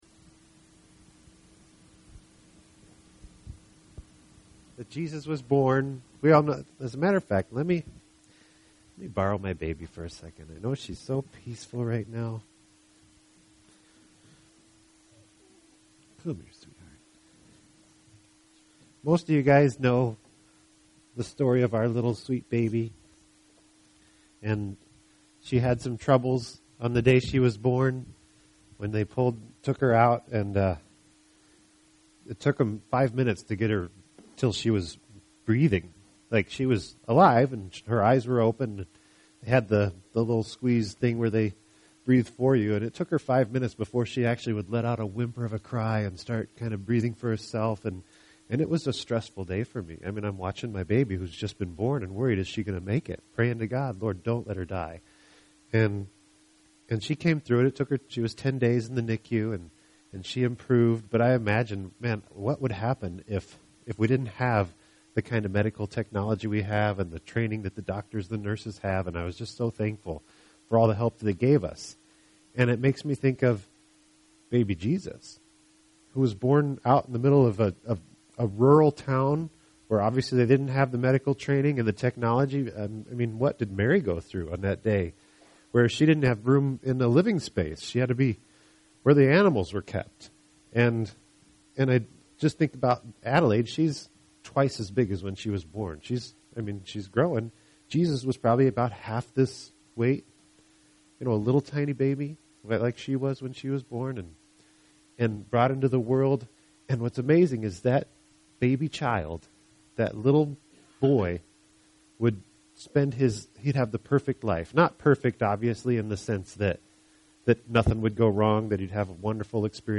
Click on the link below to listen to the talk: Podcast: Play in new window | Download (Duration: 19:08 — 8.8MB) This entry was posted on Tuesday, September 4th, 2012 at 12:00 pm and is filed under Sermons .